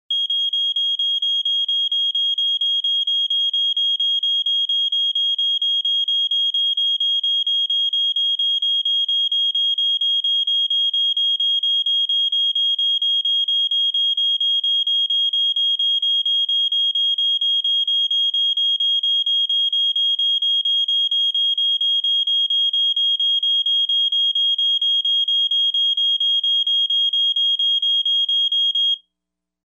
Звуки пожарной тревоги
Вы можете прослушать и скачать различные сигналы: мощную сирену промышленного объекта, прерывистый сигнал квартирного извещателя, рев системы оповещения в здании.